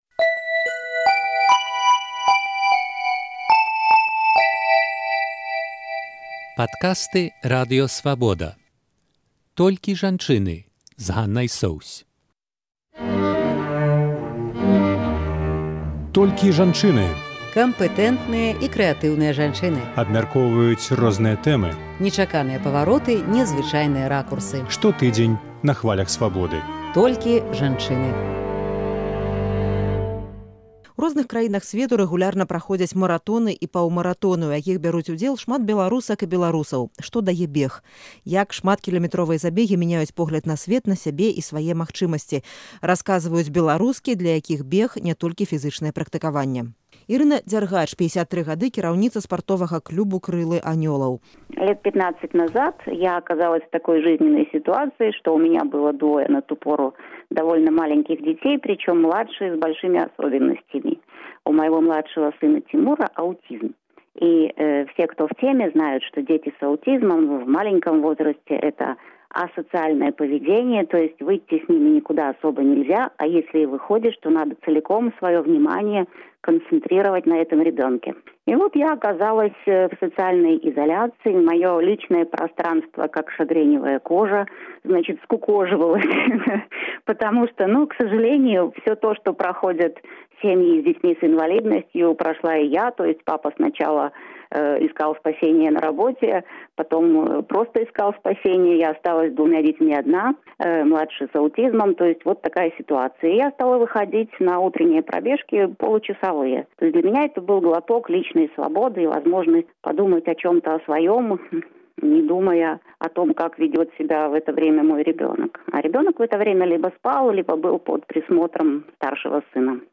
Як шматкілямэтровыя забегі мяняюць погляд на сьвет, на сябе і свае магчымасьці, расказваюць беларускі, для якіх бег — ня толькі фізычнае практыкаваньне.